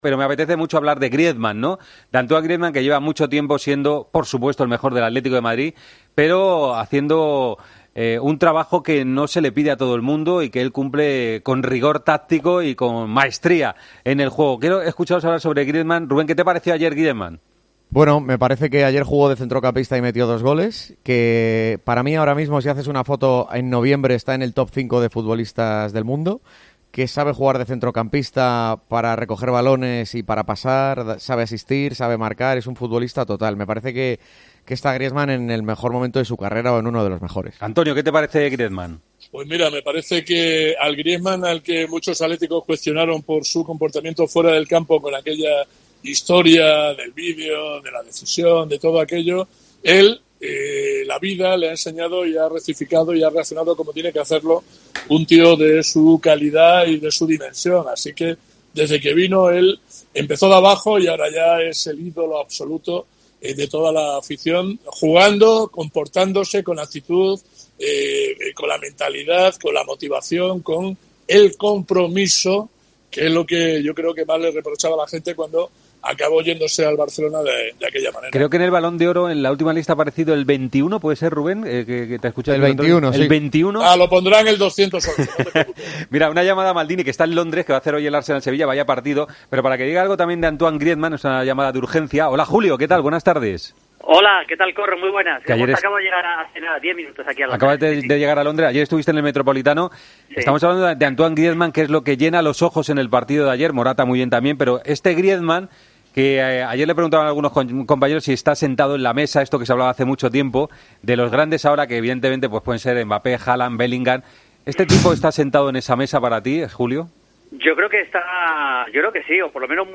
DEBATE EN DEPORTES COPE